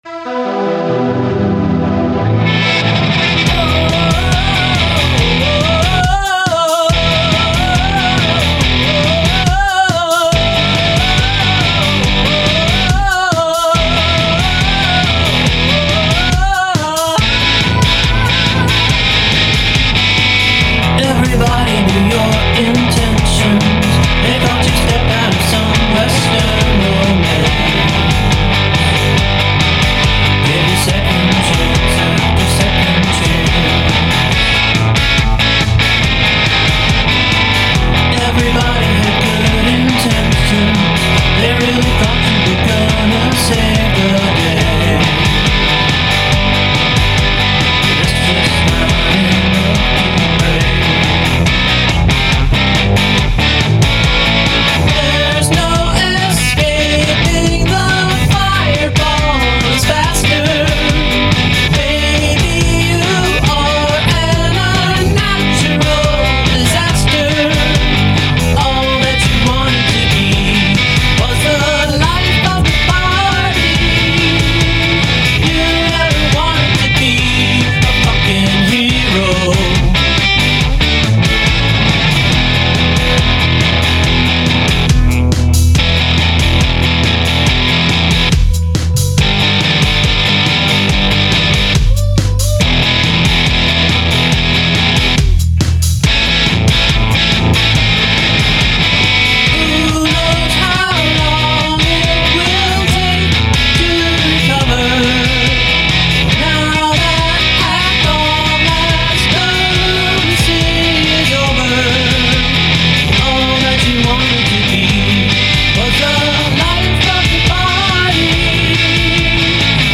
Must include prominent use of backwards recording